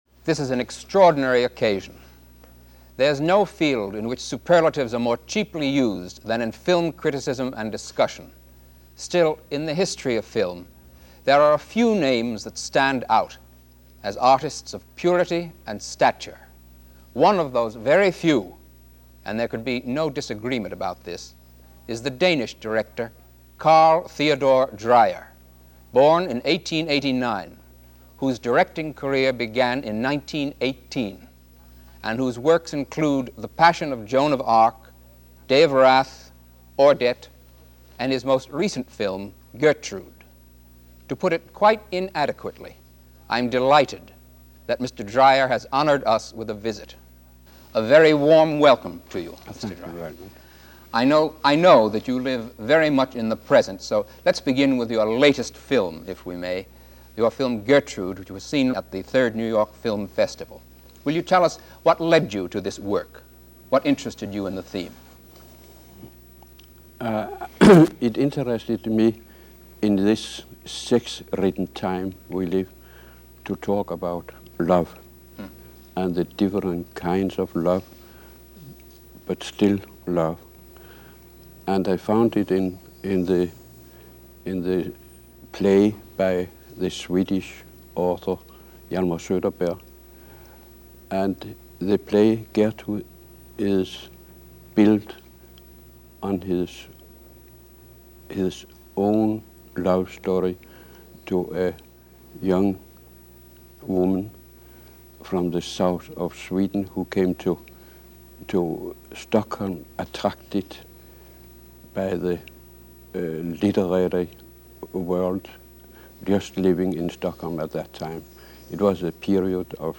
Interview with Carl Theodor Dreyer - 1965. The legendary Danish Filmmaker is interviewed regarding his career which went from 1913-1964.
Carl-Theodor-Dreyer-Interview-1965.mp3